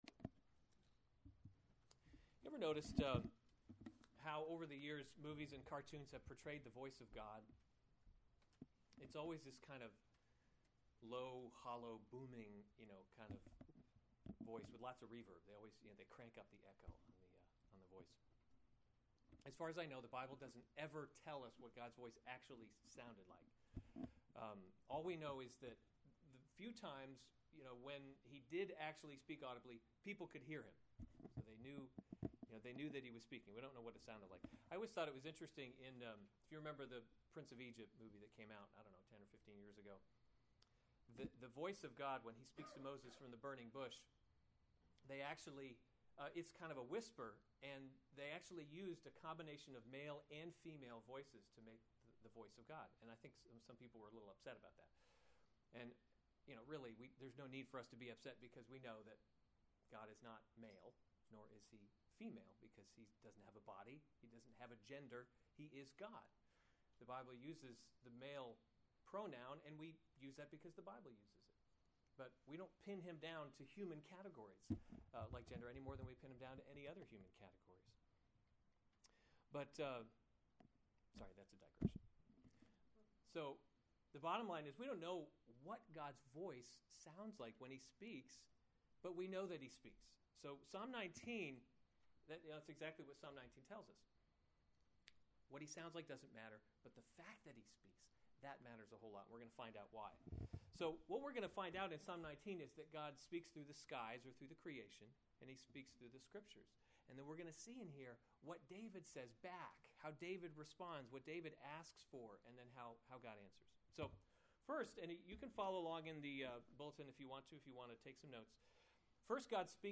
July 18, 2015 Psalms – Summer Series series Weekly Sunday Service Save/Download this sermon Psalm 19 Other sermons from Psalm The Law of the Lord Is Perfect To the choirmaster.